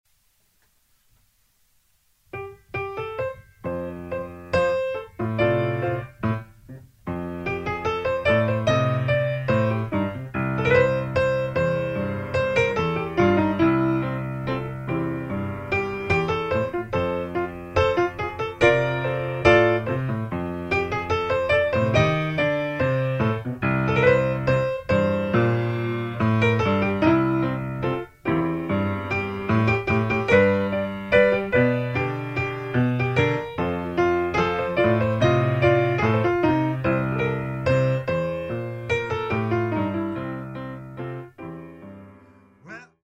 Style: Barrelhouse Piano